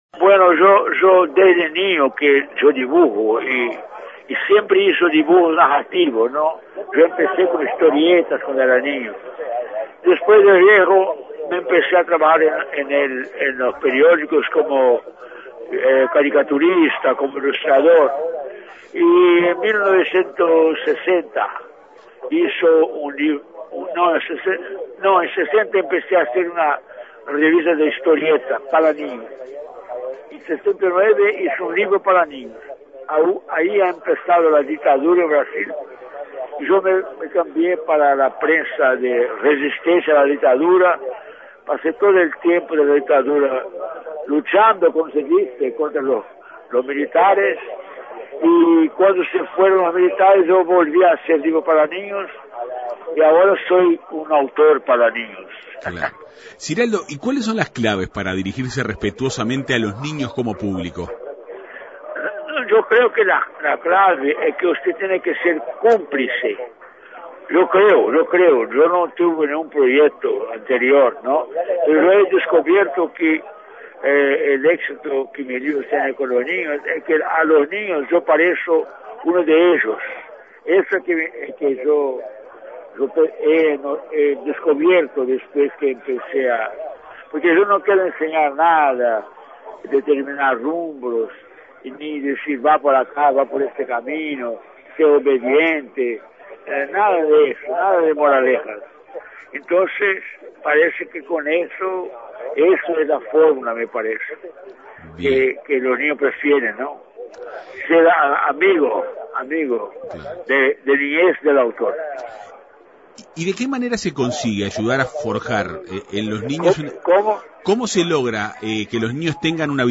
El fin de semana pasado, con motivo de la Feria del Libro pasó por Montevideo el escritor brasileño Ziraldo. El dibujante, pintor, periodista, dramaturgo y, sobre todo, uno de los autores infantiles más populares de América Latina dialogó en la Segunda Mañana de En Perspectiva.